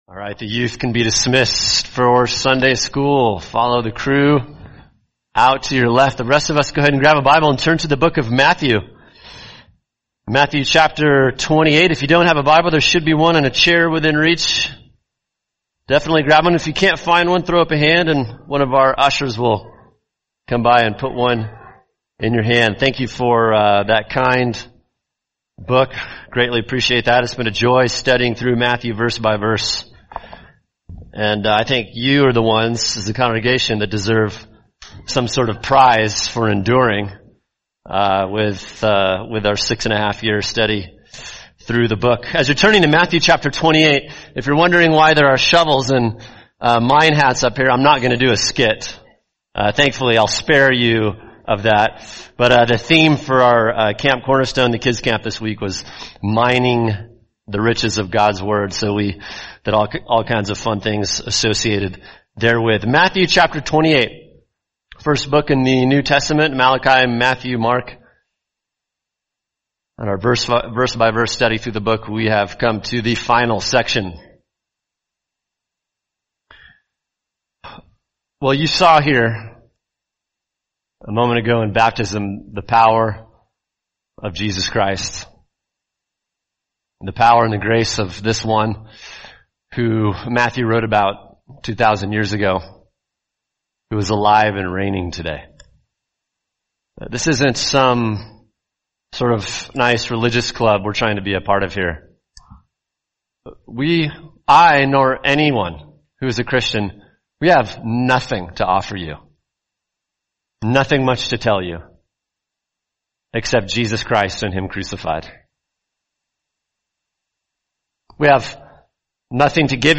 [sermon] Matthew 28:16-20 Every Christian’s Mission – Part 3: Disciple Making Disciples | Cornerstone Church - Jackson Hole